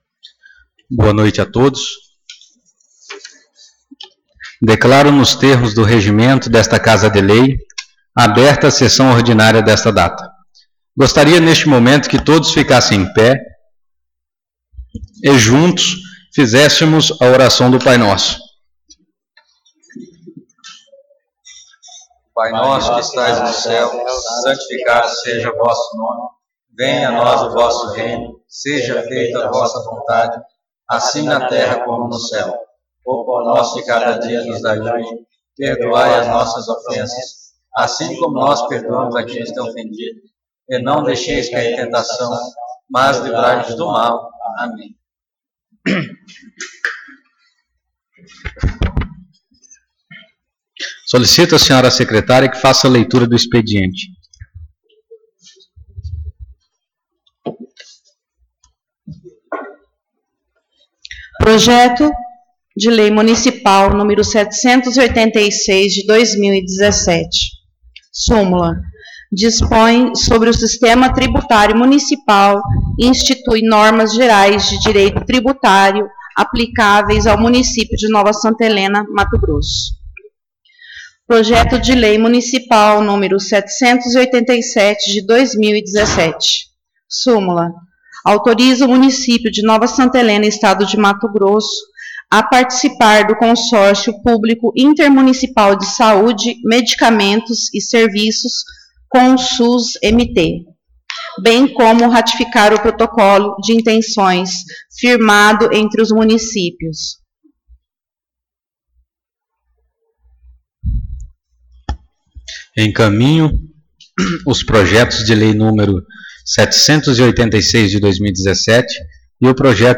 Sessão Ordinária 27/11/2017